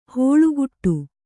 ♪ hōḷuguṭṭu